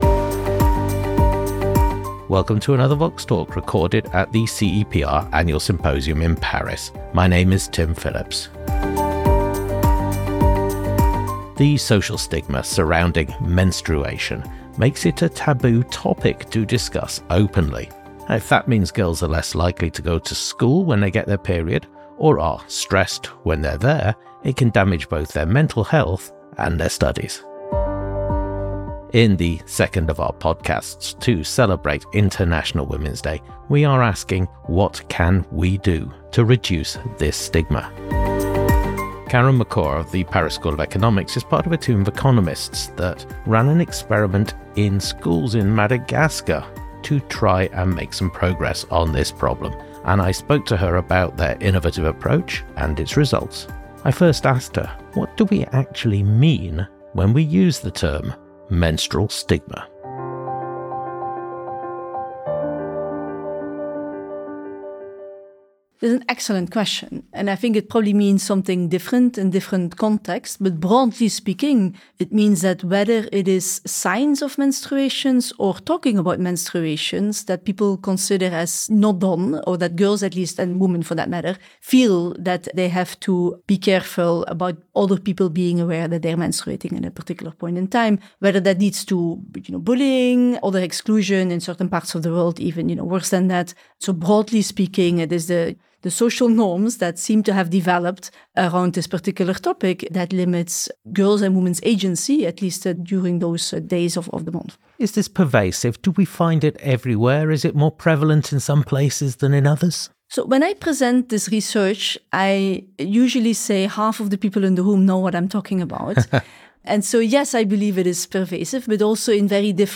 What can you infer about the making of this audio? Recorded at the CEPR Paris Symposium. Everywhere in the world there is still a stigma around periods. That can lead to bullying or exclusion in schools.